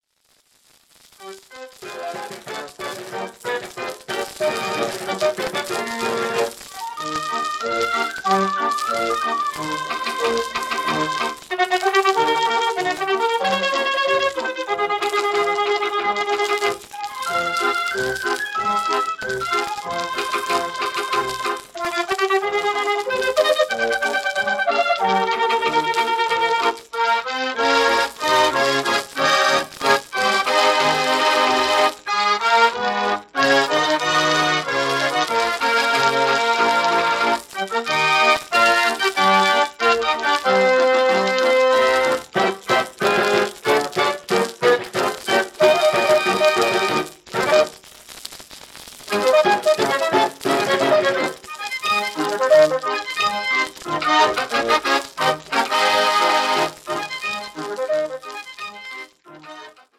afwisselende orgelopname in dansante sfeer.
Uitvoerend orgel
Formaat 78 toerenplaat, 10 inch
Fox-trot